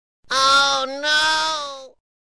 • Категория: Отрицание - нет
• Качество: Высокое